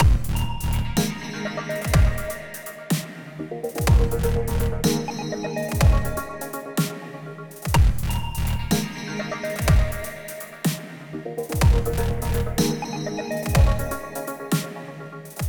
Вот слепил на коленке, всего 8 треков на всех реверки разные, один кусок 41 второй 96.
Темп 124 для заloopпить .